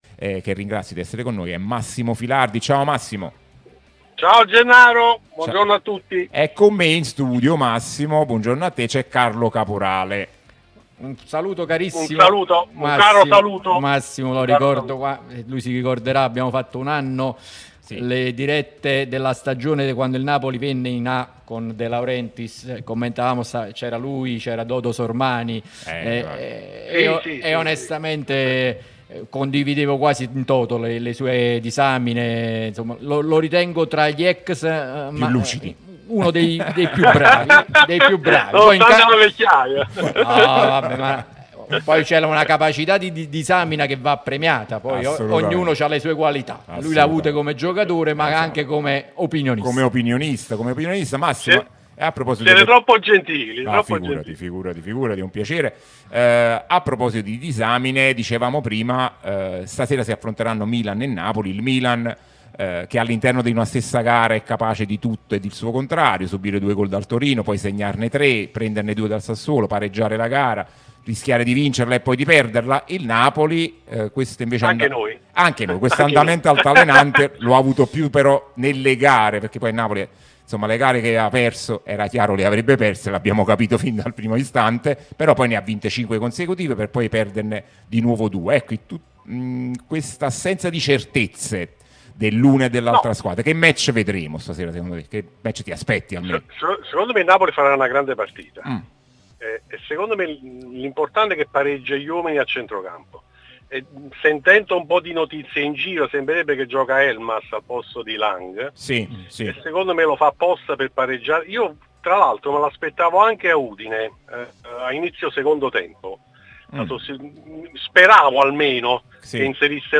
trasmissione sulla nostra Radio Tutto Napoli